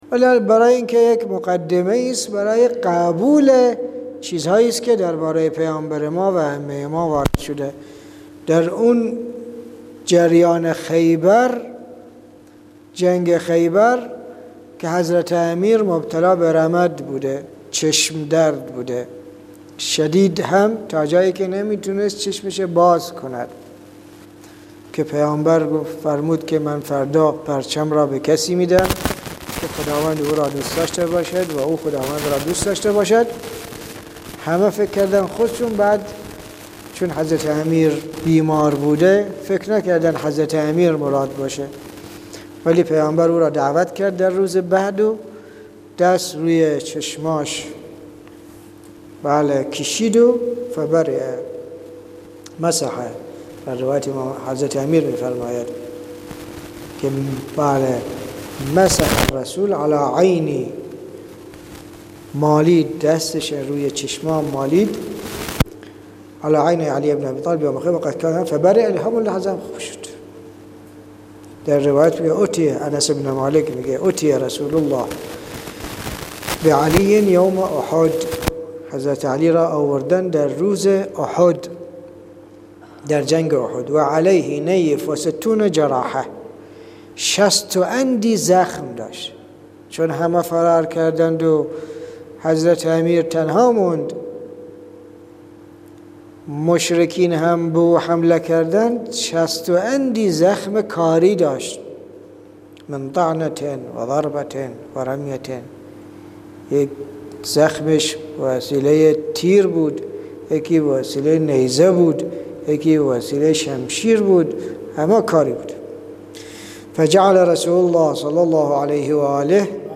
صوت تدریس جلد 2 ، جلسه 7